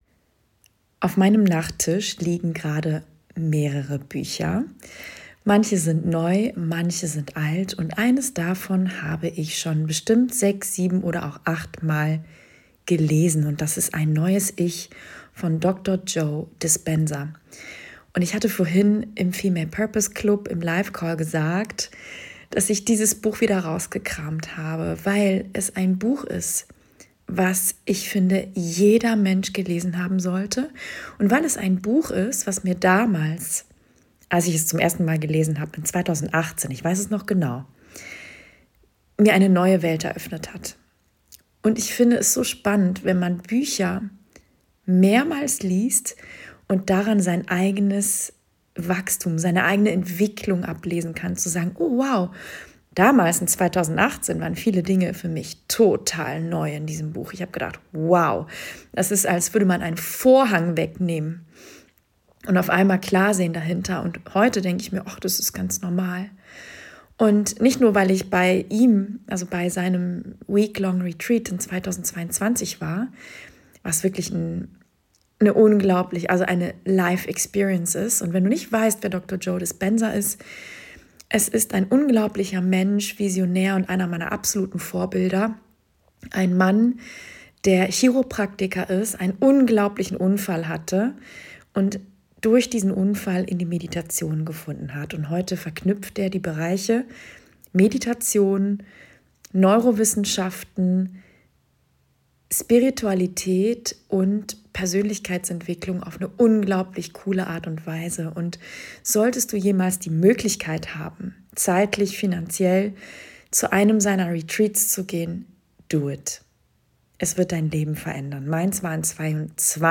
Real, raw und ungeschnitten.